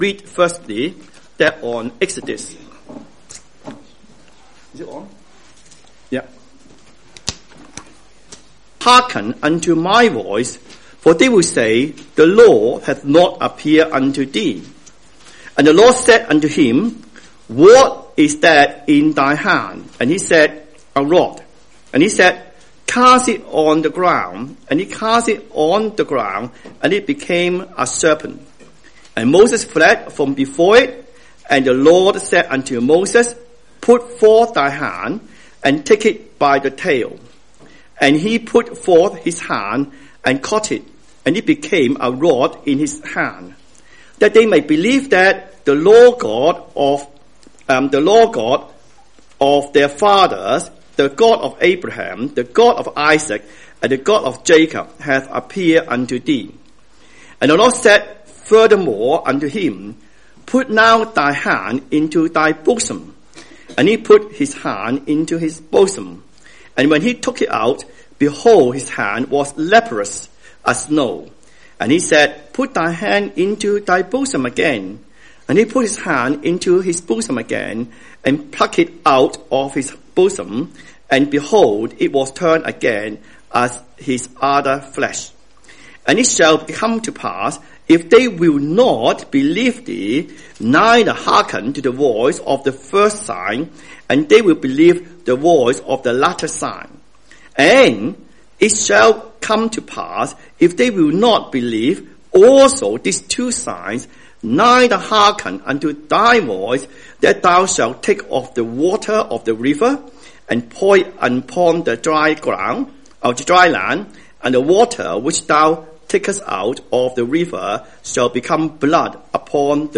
Sunday Family Service